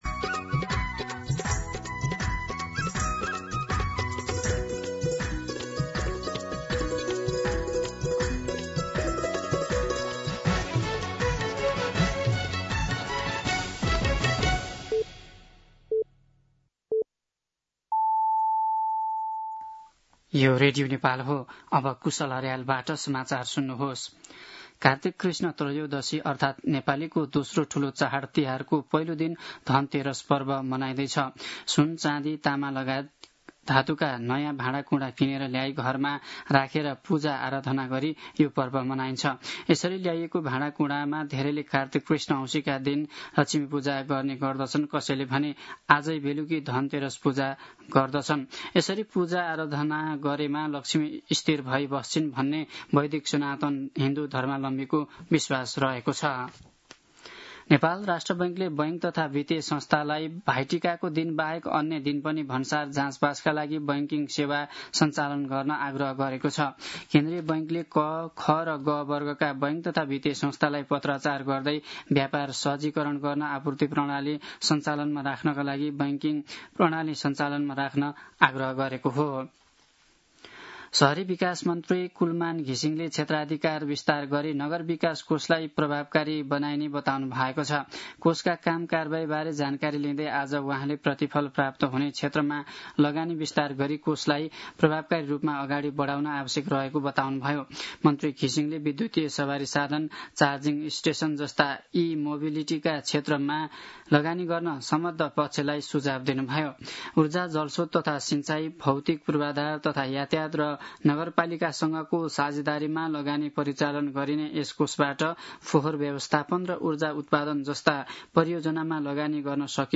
दिउँसो १ बजेको नेपाली समाचार : १ कार्तिक , २०८२
1-pm-Nepali-News-9.mp3